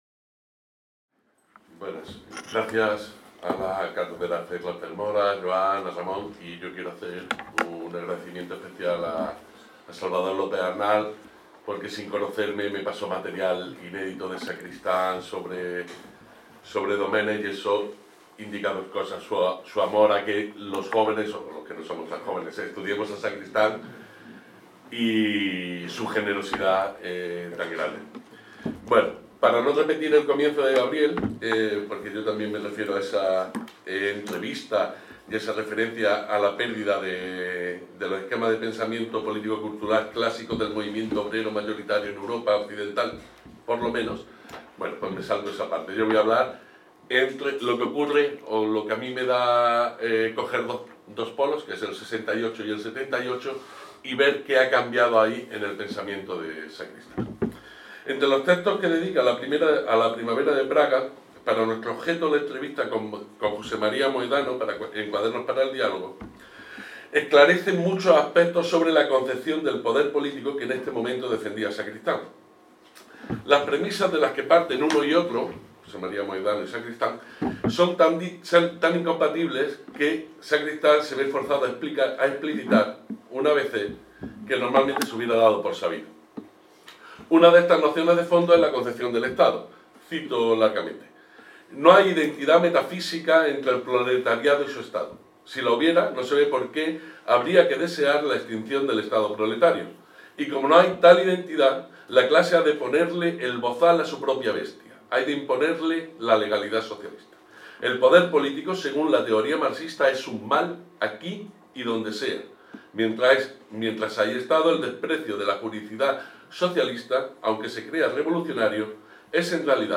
En el Simposi Trias 2025, organitzat per la Càtedra Ferrater Mora, en col·laboració amb el Memorial Democràtic i dedicat al filòsof Manuel Sacristán